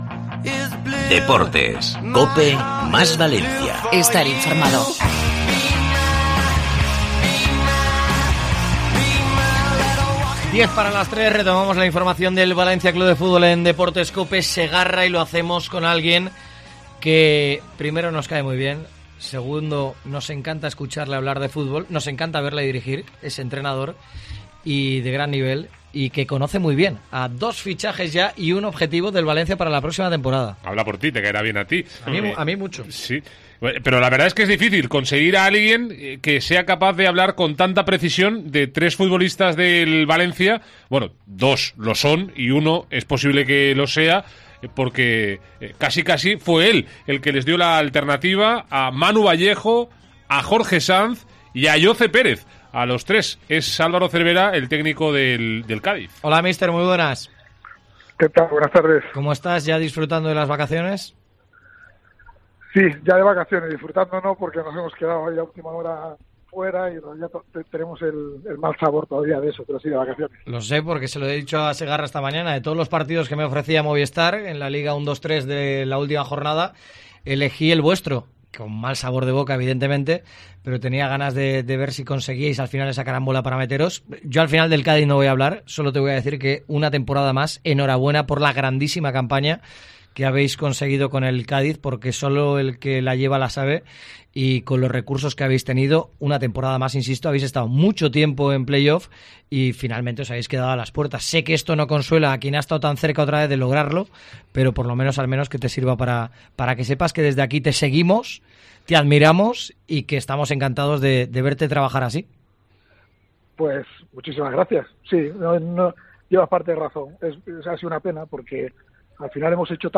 Entrevista a Álvaro Cervera en Deportes COPE Valencia